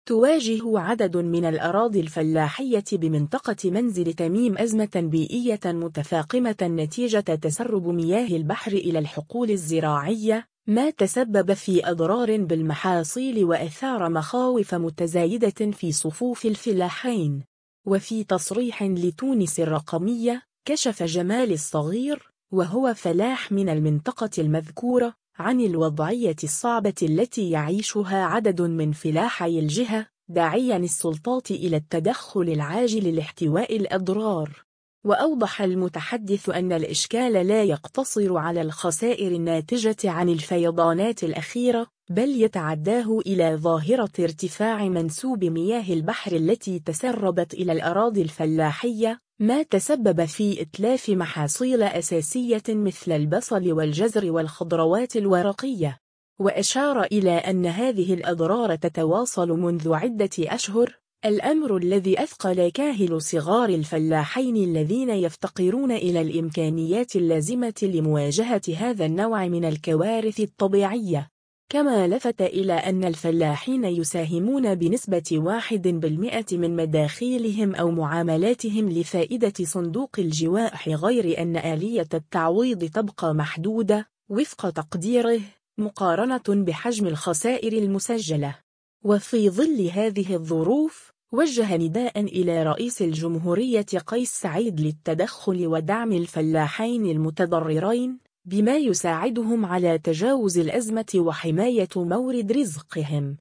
وفي تصريح لـتونس الرقمية